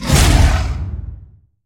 Sfx_creature_bruteshark_flinch_02.ogg